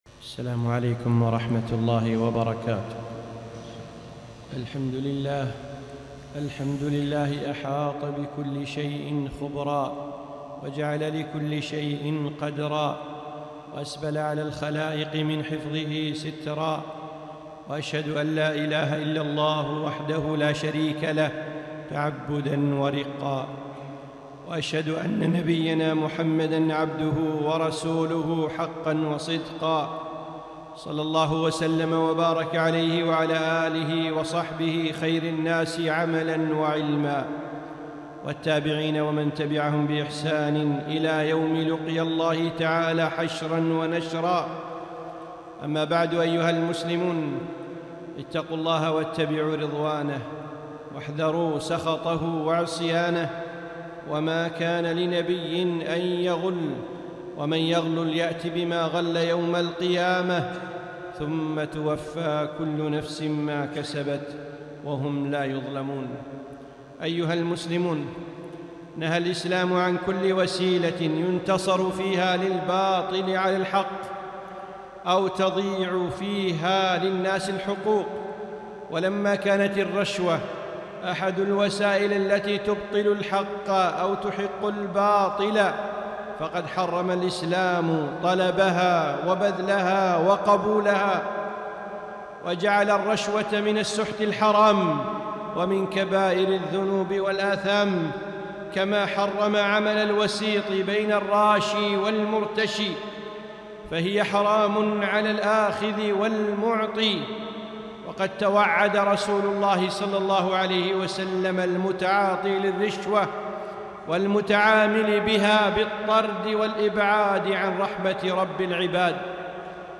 خطبة - الرشوة